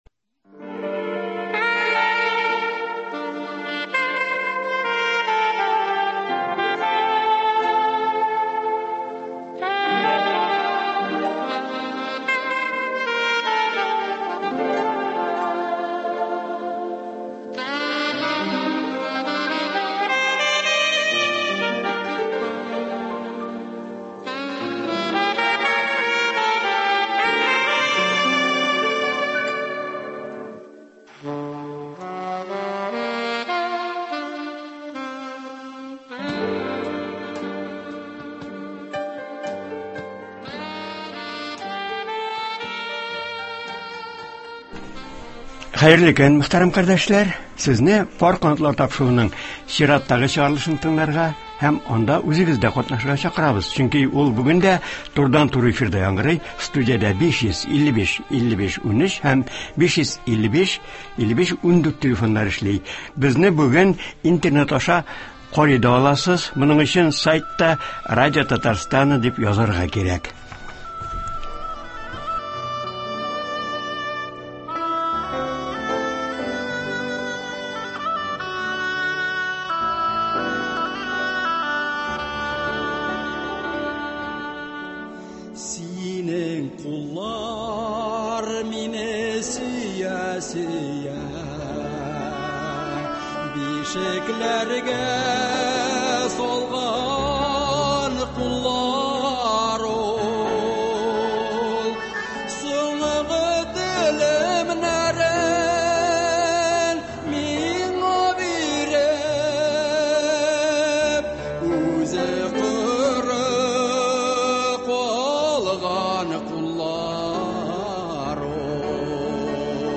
турыдан-туры эфирда
радиотыңлаучылар белән аралаша, аларның сорауларына җавап бирә.